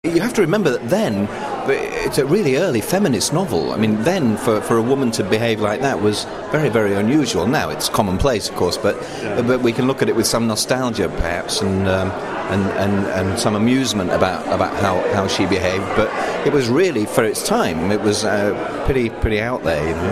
Here are a few short audio extracts from my chat with Peter: